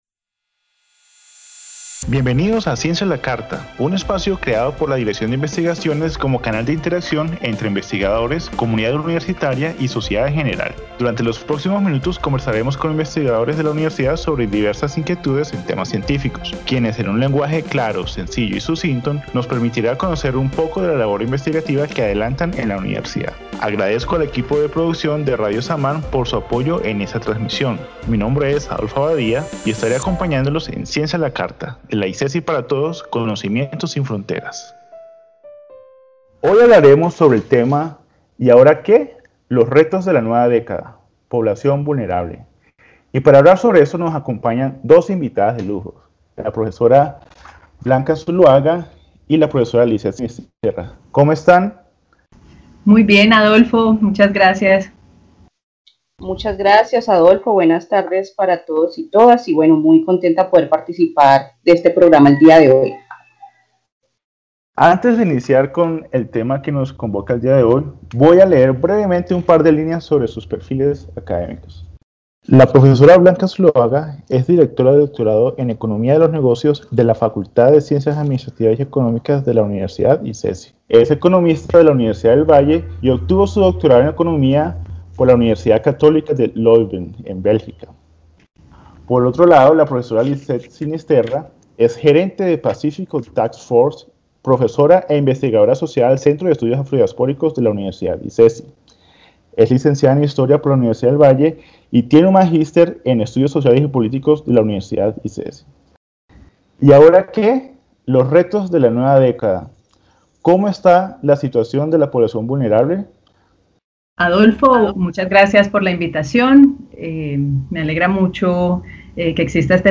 ¿Y ahora qué? Los retos de la nueva década: Población vulnerable. En el primer momento del programa, las investigadoras invitadas tendrán un espacio para reaccionar, libremente, al tema del día y, posteriormente, se traerán a la mesa preguntas formuladas previamente por el público para ser abordadas por medio del diálogo con las expertas invitadas.